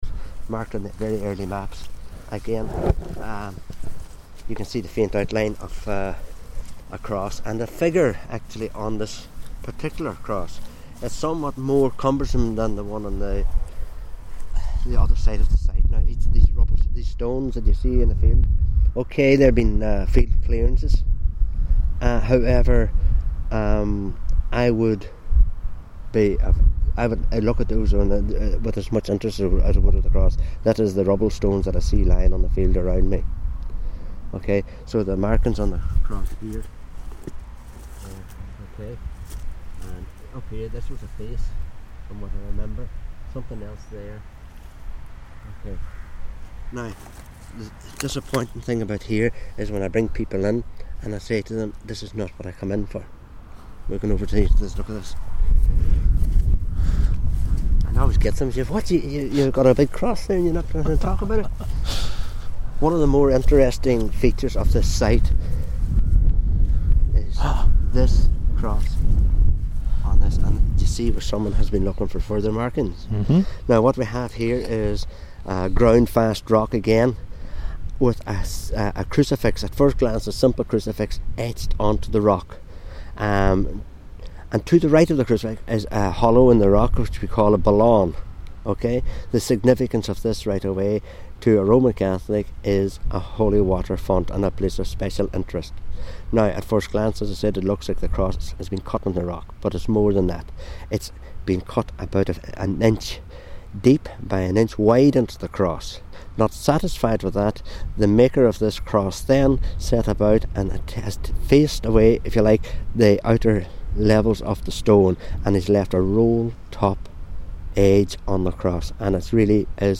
out and about in Inishowen